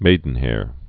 (mādn-hâr)